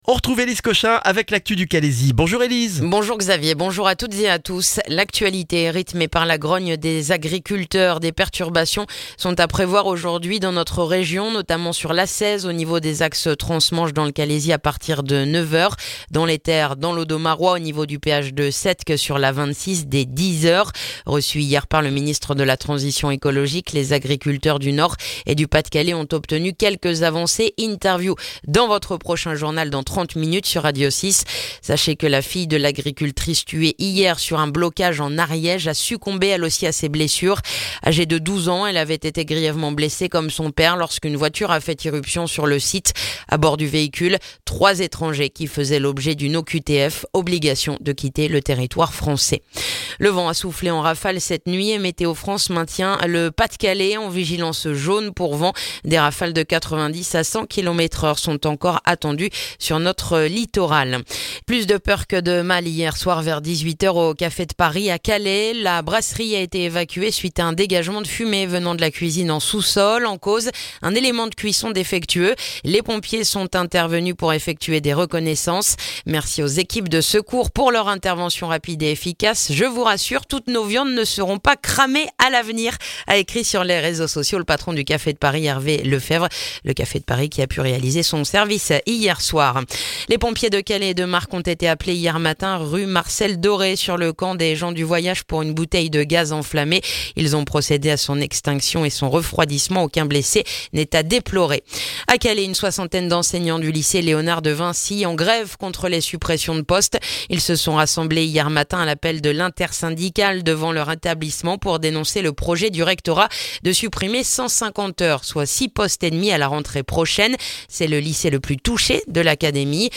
Le journal du mercredi 24 janvier dans le calaisis